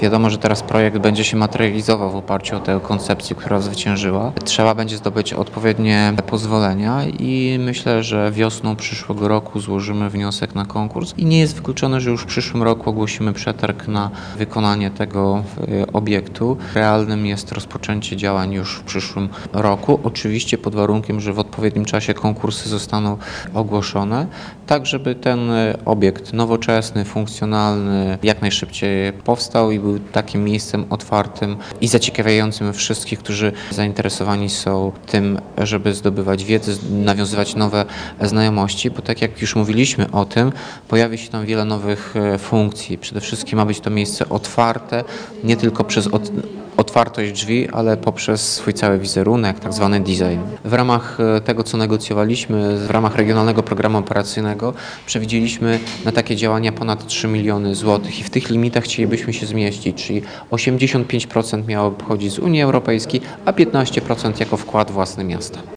-Pieniądze na przebudowę biblioteki chcemy zdobyć z funduszy unijnych. Jeżeli uda się zdobyć dofinansowanie, prace ruszą w przyszłym roku – mówi prezydent Ełku, Tomasz Andrukiewicz.